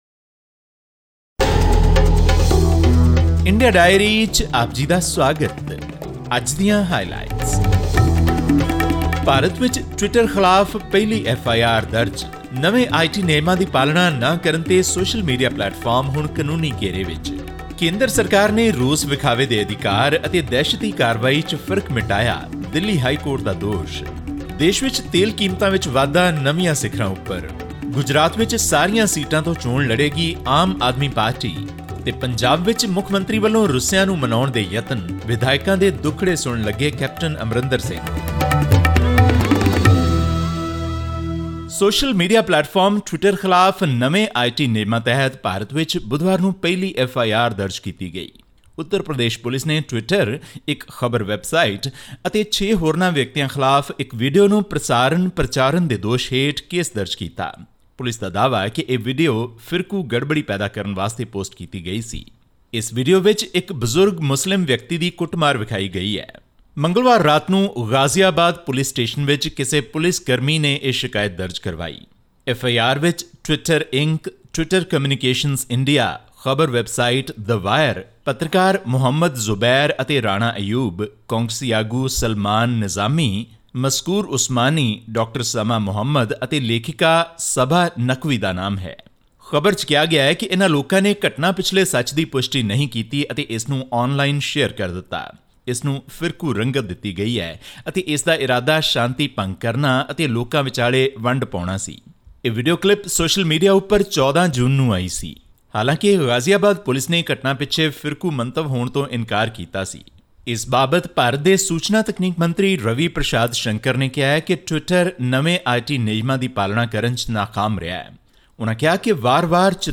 Aam Aadmi Party (AAP) chief and Delhi chief minister Arvind Kejriwal announced that the party will be contesting all 182 seats in next year’s Gujarat assembly polls. All this and more in our weekly news segment from India.